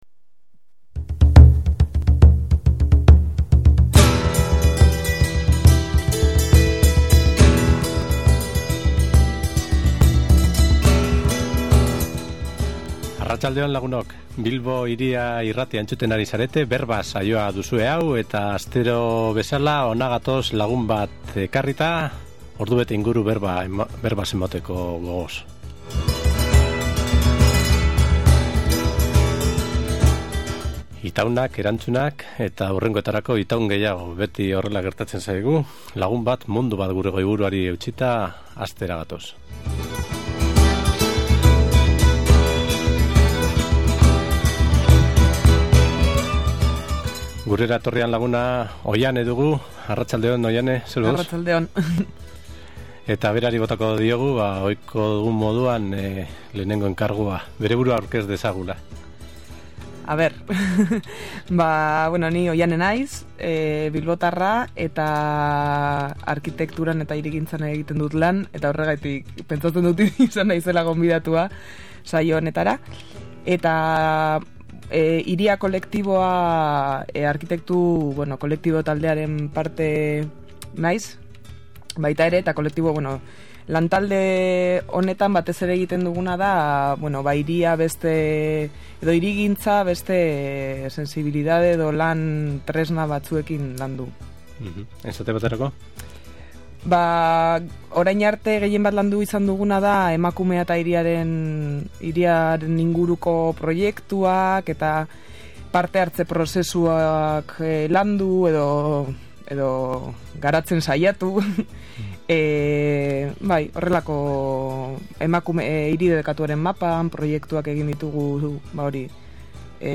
elkarrizketa aberatsean.